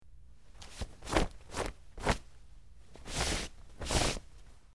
Cloth, Wipe
Brushing Off Clothes With Hands, X6